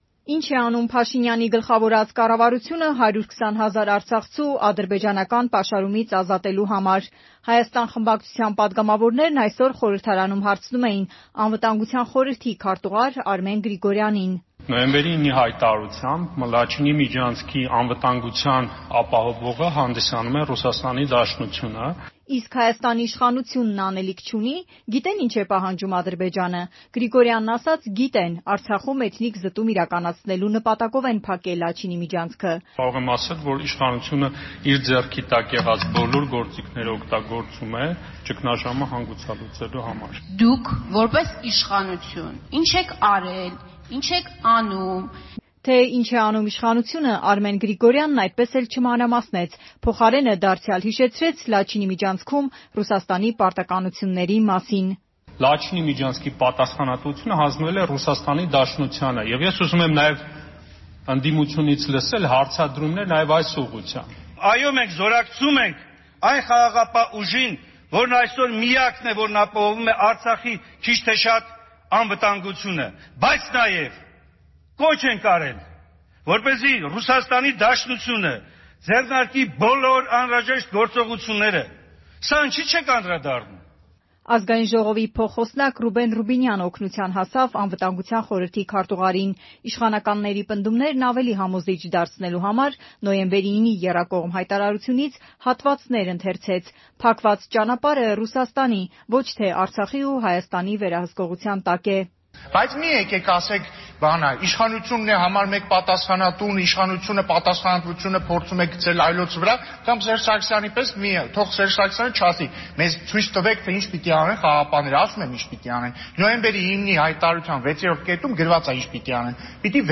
Ի՞նչ է անում ՀՀ կառավարությունը արցախցիներին պաշարումից ազատելու համար. թեժ բանավեճ ԱԺ-ում
Ռեպորտաժներ